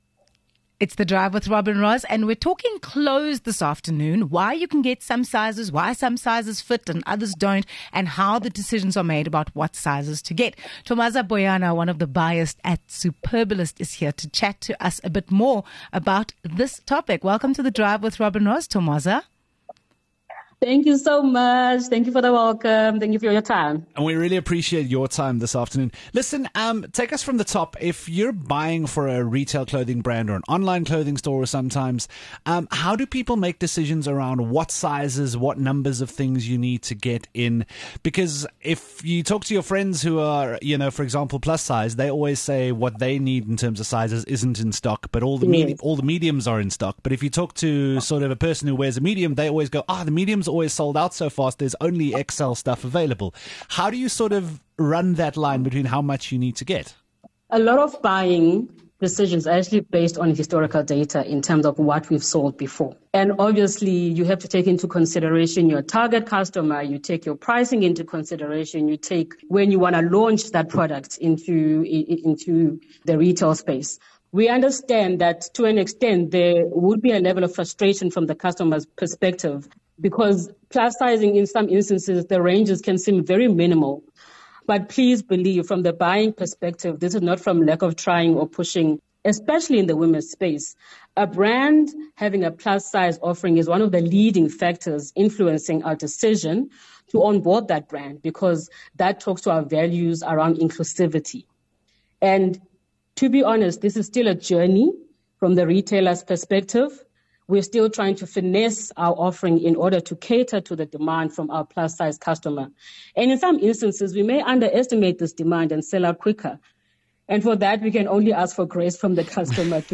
27 Mar Fashion buyer explains why sizes vary at different stores